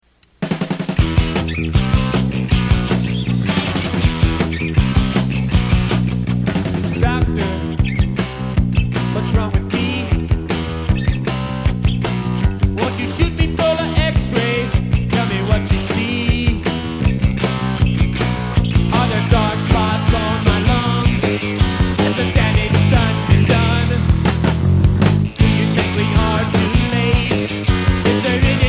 So this is from a cassette as well, a rough mix probably...
guitar
and I'm on the bass.